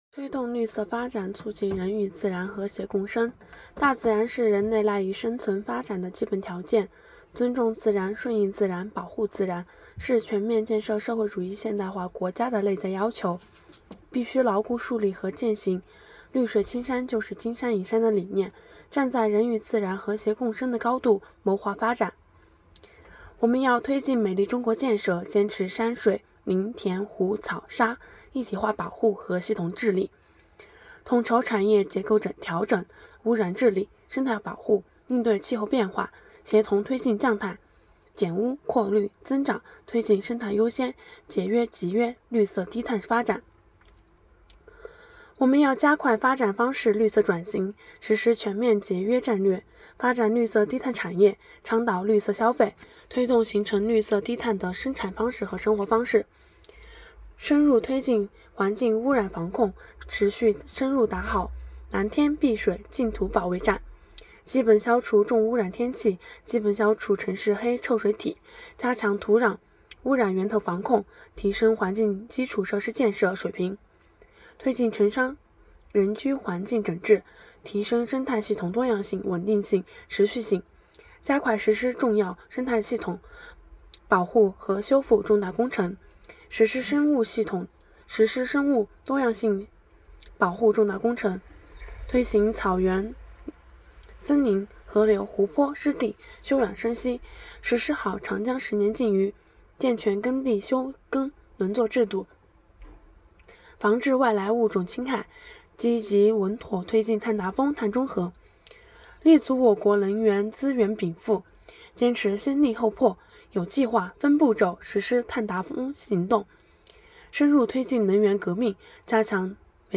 "喜迎二十大 经典咏流传"——西华大学马克思主义经典著作研读会接力诵读（003期）